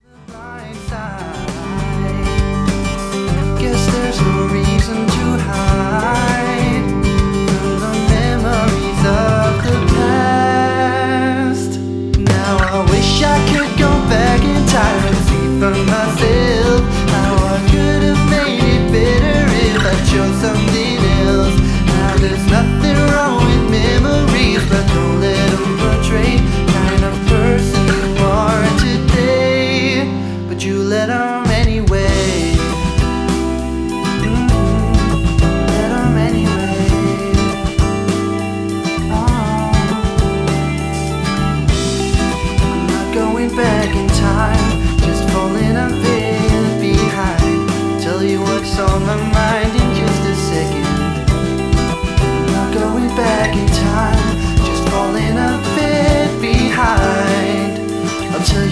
• Blues
• Country
• Pop
• Singer/songwriter